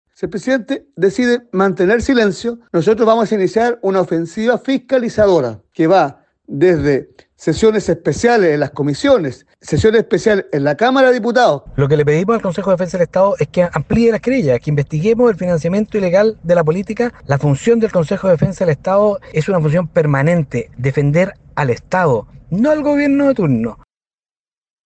Además, según señalaron los diputados gremialistas, Henry Leal y Felipe Donoso, solicitaron al Consejo de Defensa del Estado (CDE) ampliar la querella presentada en 2024 contra ProCultura, incluyendo el eventual uso irregular de fondos públicos en campañas políticas.